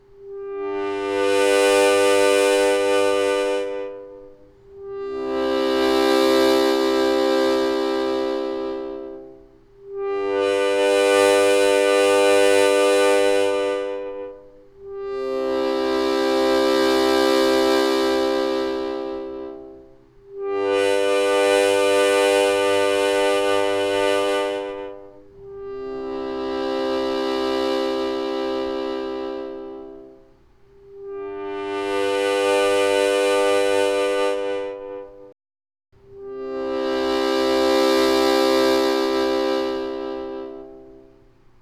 Играть на средней громкости. Чередовать аккорды на вдох и на выдох на первых трёх отверстиях: -(123)+(123)